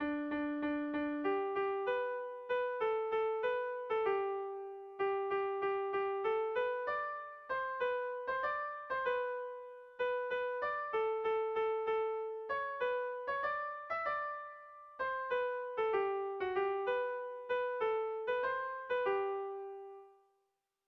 Irrizkoa
Baztan < Baztan Ibarra < Iruñeko Merindadea < Nafarroa < Euskal Herria
Zortziko txikia (hg) / Lau puntuko txikia (ip)
ABDE